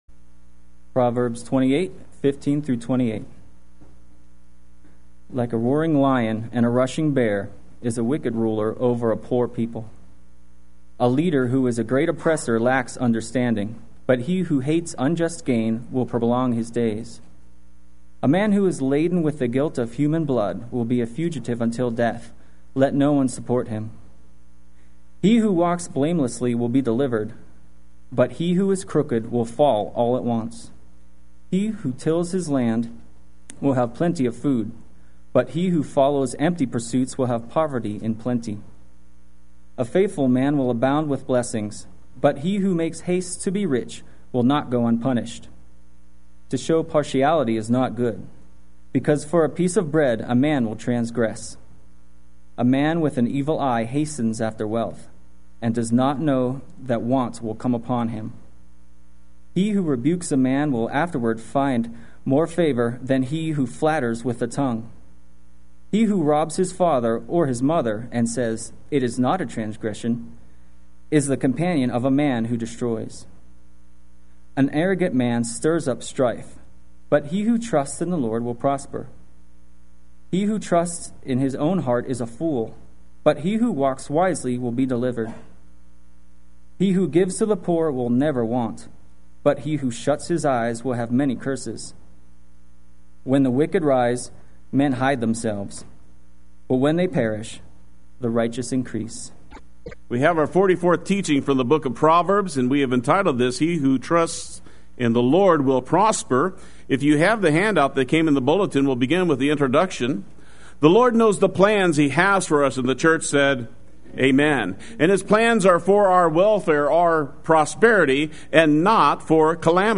Play Sermon Get HCF Teaching Automatically.
He Who Trusts in the Lord Will Prosper Sunday Worship